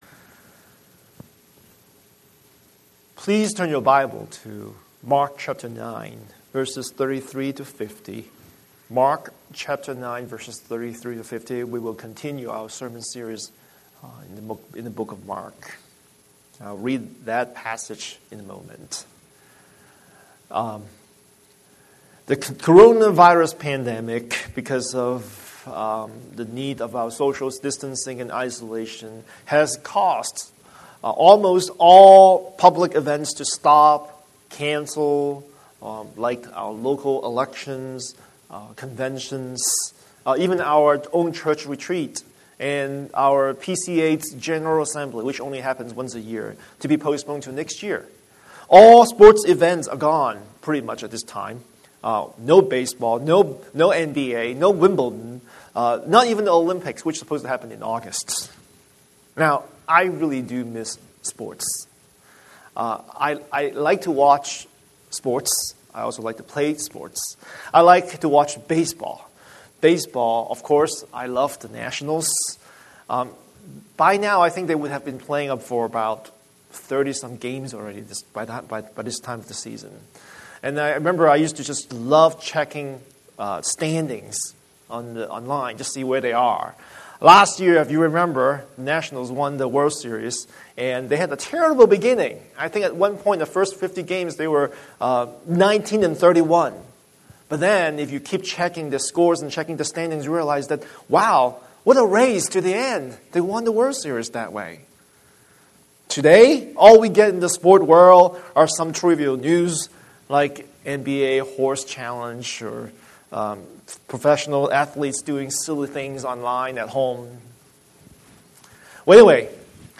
Scripture: Mark 9:33-9:50 Series: Sunday Sermon